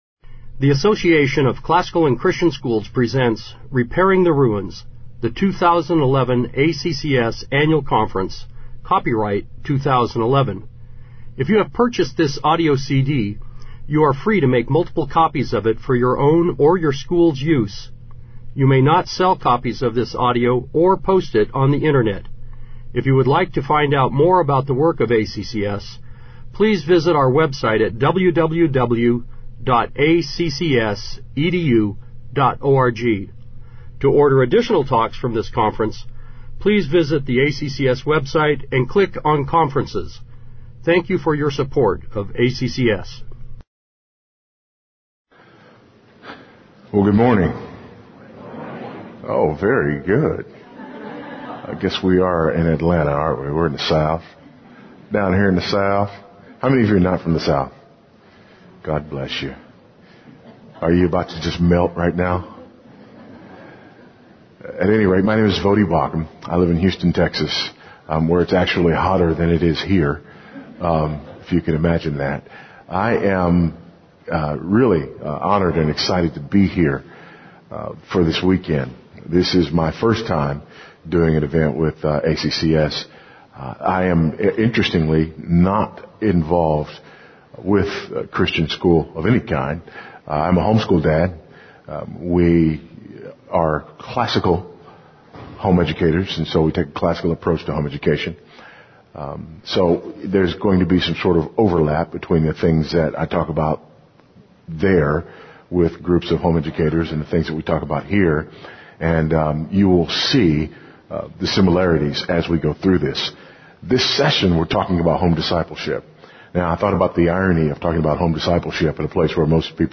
2011 Workshop Talk | 0:56:56 | All Grade Levels, Virtue, Character, Discipline